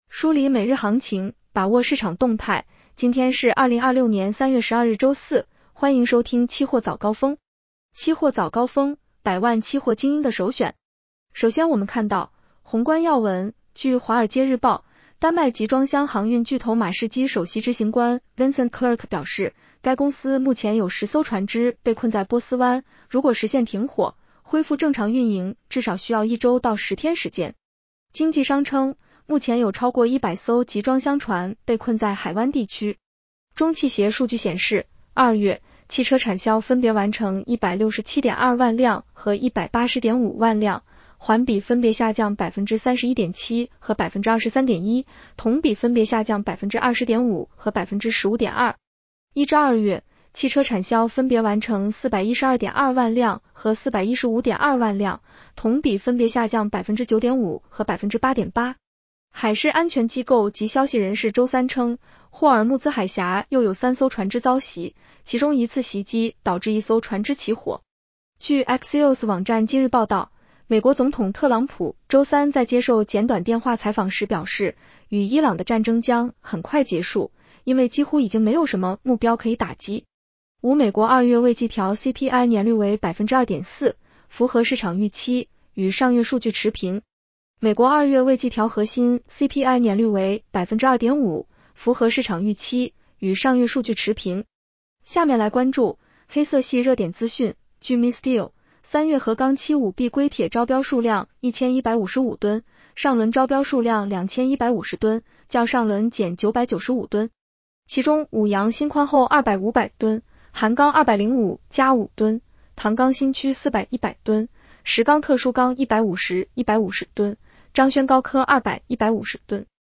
期货早高峰-音频版 女声普通话版 下载mp3 热点导读 1.郑商所调整苹果期货部分合约交易保证金标准和涨跌停板幅度。